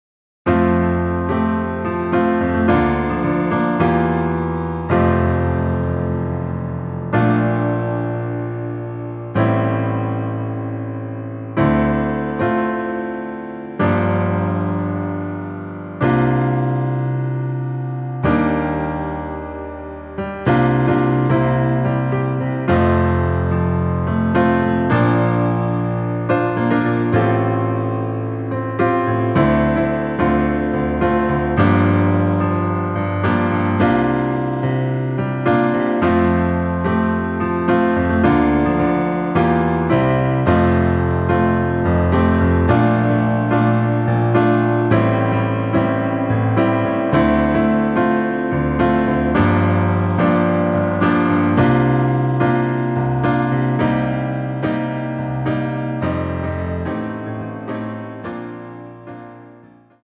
노래 바로 시작 하는 곡이라 전주 2마디 만들어 놓았습니다.(미리듣기 참조)
앞부분30초, 뒷부분30초씩 편집해서 올려 드리고 있습니다.
중간에 음이 끈어지고 다시 나오는 이유는